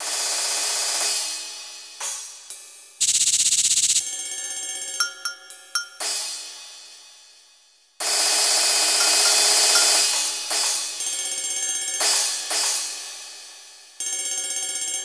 Percusión 2 (Metales) Breve pieza.
platillo
idiófono
triángulo
pandereta
percusión
crótalo
Instrumentos musicales